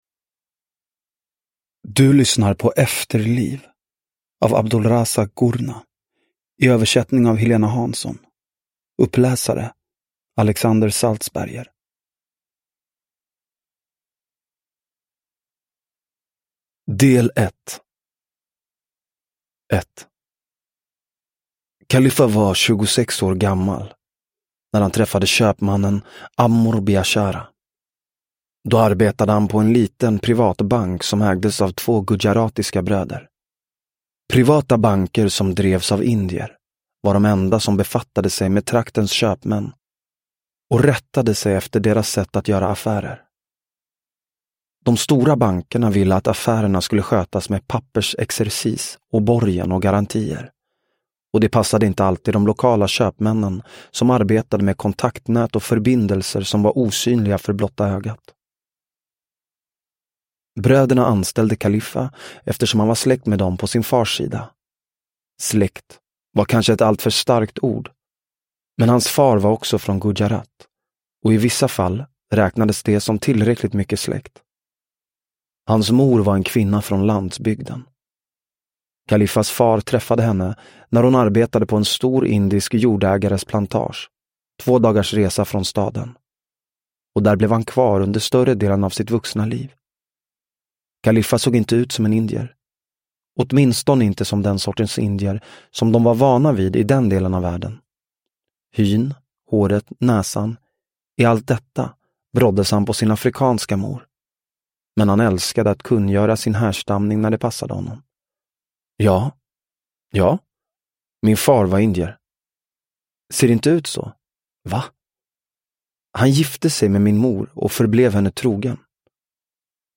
Efterliv – Ljudbok – Laddas ner